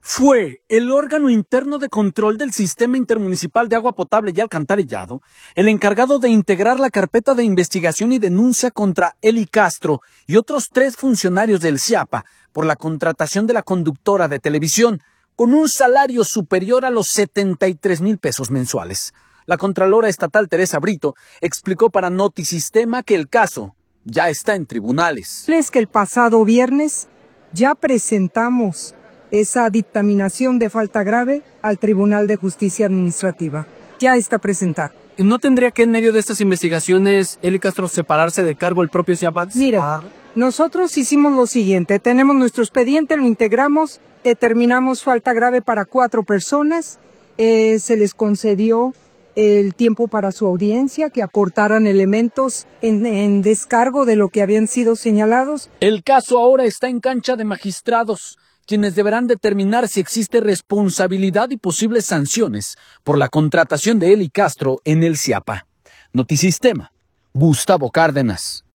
La contralora estatal, Teresa Brito, explicó para Notisistema que el caso ya está en tribunales.